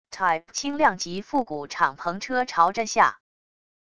type轻量级复古敞篷车朝着下wav音频